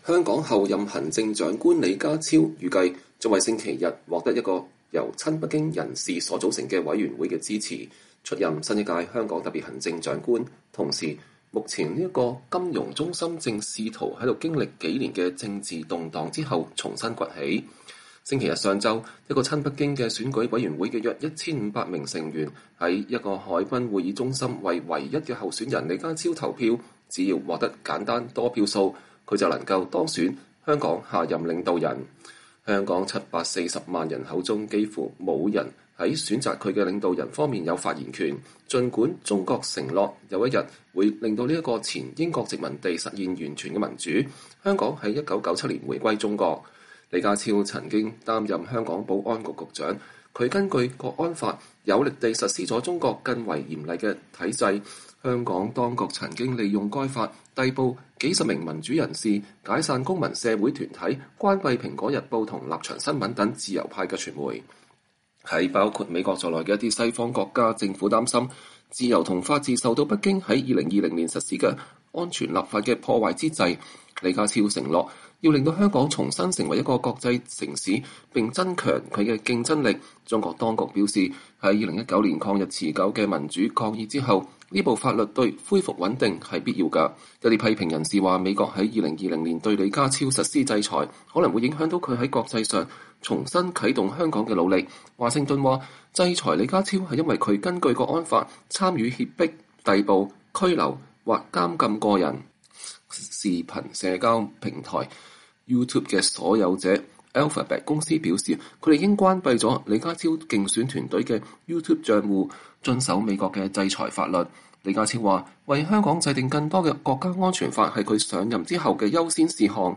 香港特首選舉唯一候選人李家超在造勢大會上發言，透露小時候被人欺負的經歷，令他立志長大後做警察。